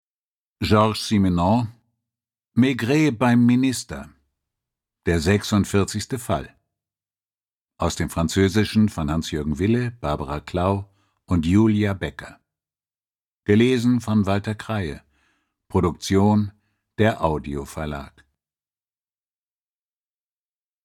Walter Kreye (Sprecher)
Ungekürzte Lesung